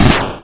ihob/Assets/Extensions/explosionsoundslite/sounds/bakuhatu116.wav at master
bakuhatu116.wav